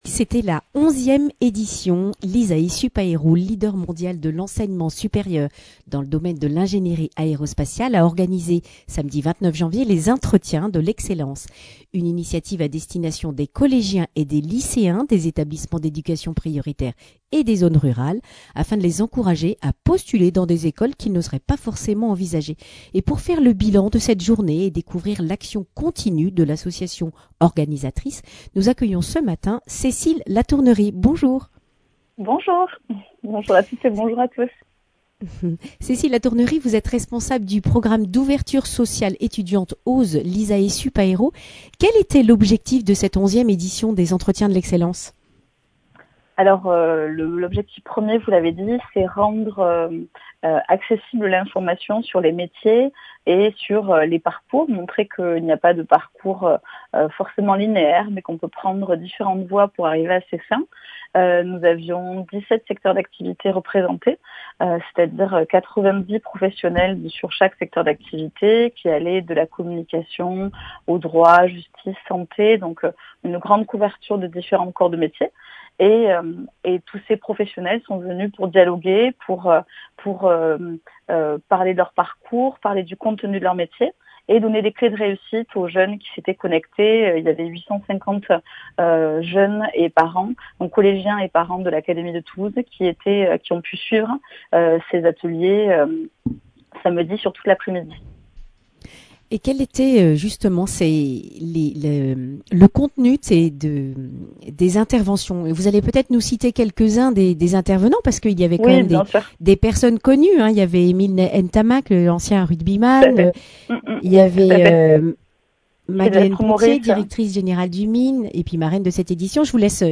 Accueil \ Emissions \ Information \ Régionale \ Le grand entretien \ Des étudiants d’ISAE SUPAERO au service des collégiens et lycéens (…)